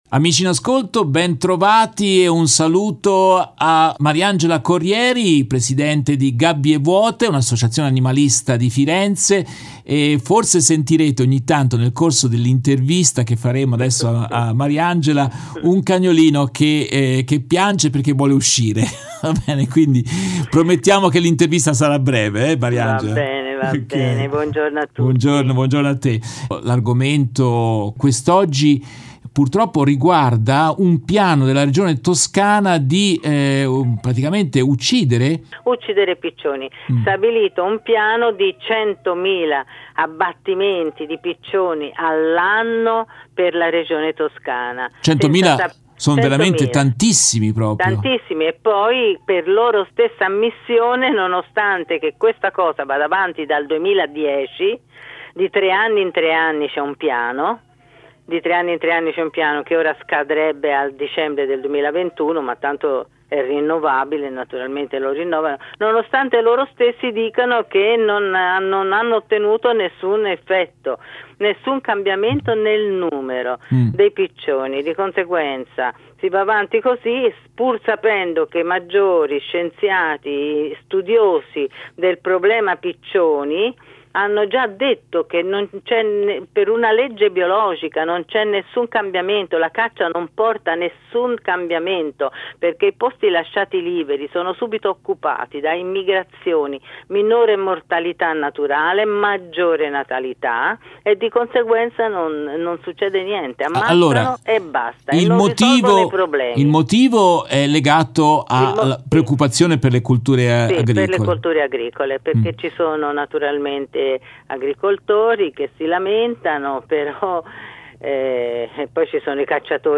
In questa intervista tratta dalla diretta RVS del 17 dicembre 2021